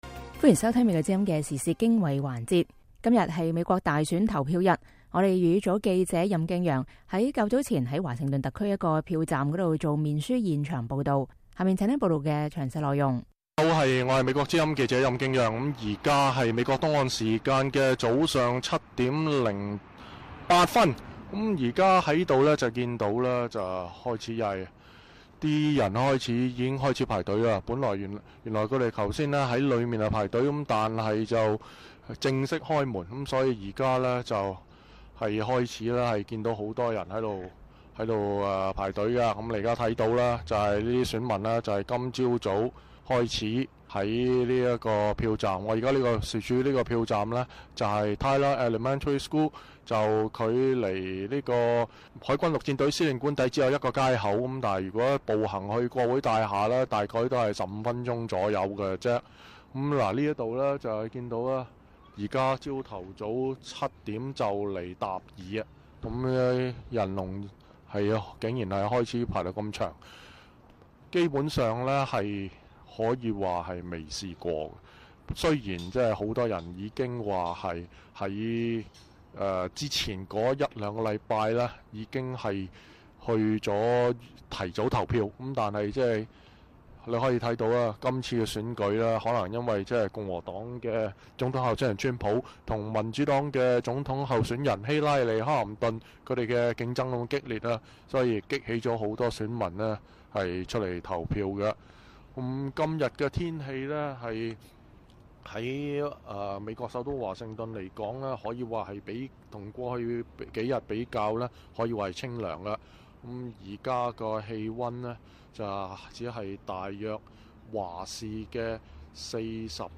美國之音粵語組在DC投票站用面書現場報導